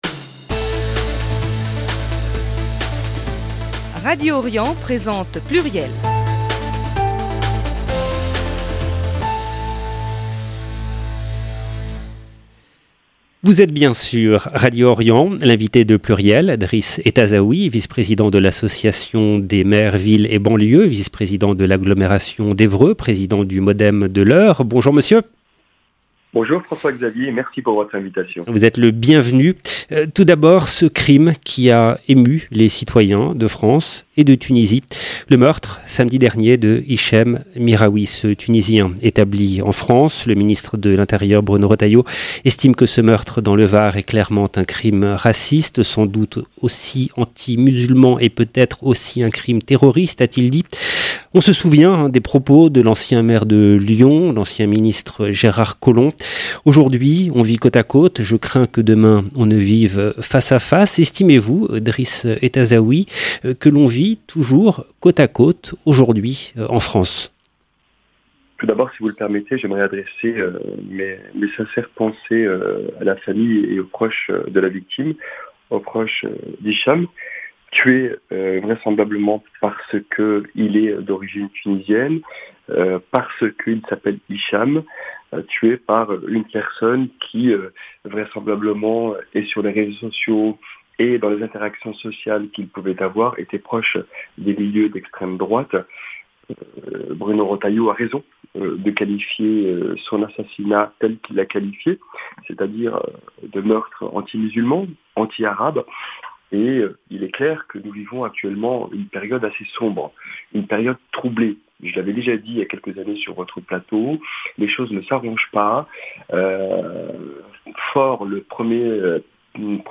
L’invité de pluriel : Driss Ettazaoui, Vice Président de l’association des Maires Ville & Banlieue.